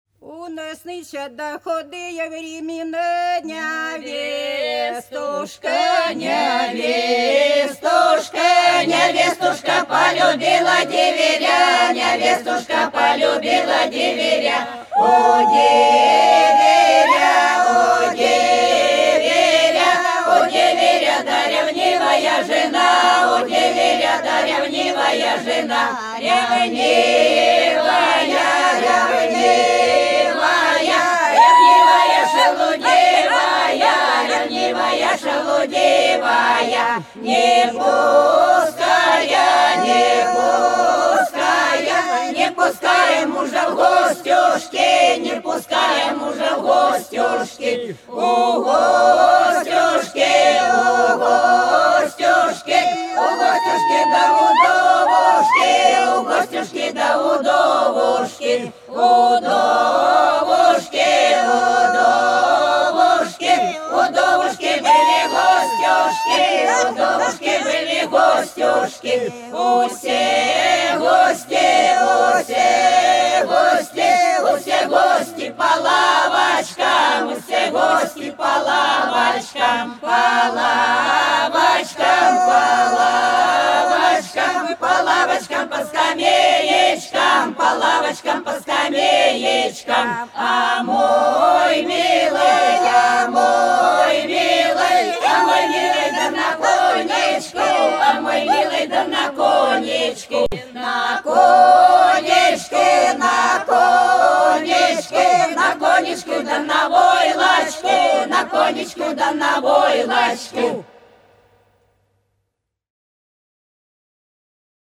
По-над садом, садом дорожка лежала У нас нынче да худые времена - плясовая (с.Фощеватово, Белгородская область)